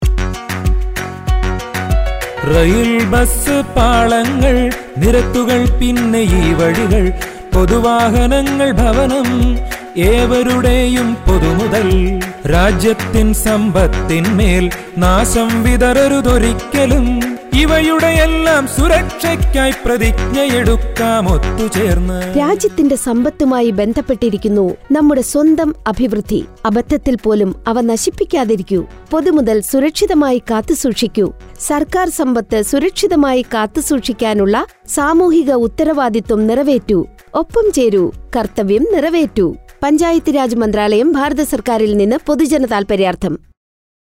132 Fundamental Duty 9th Fundamental Duty Safeguard public property Radio Jingle Malayamlam